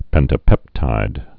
(pĕntə-pĕptīd)